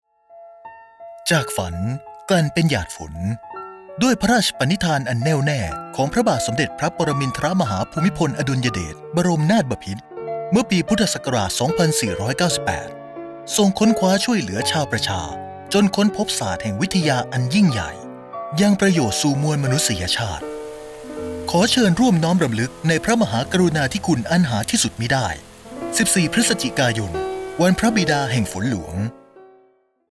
ดาวน์โหลดไฟล์สปอตวิทยุ วันพระบิดาแห่งฝนหลวงประจำปี 2560